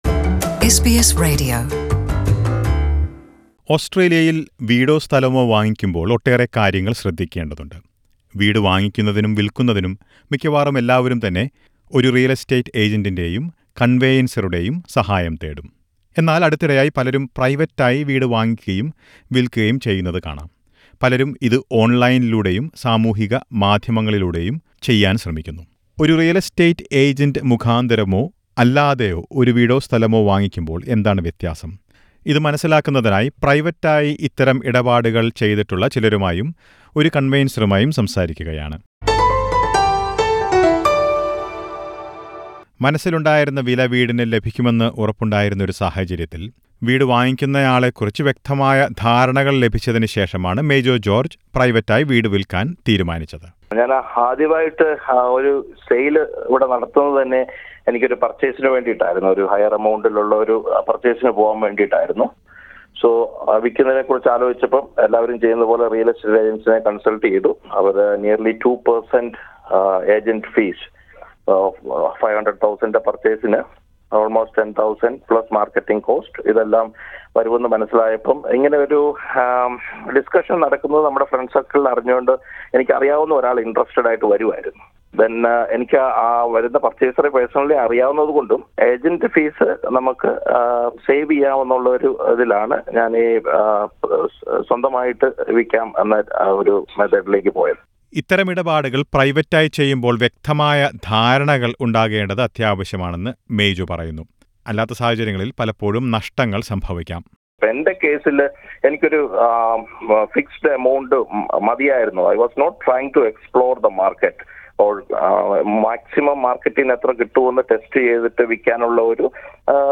What to look out for in a private sale? Listen to a report.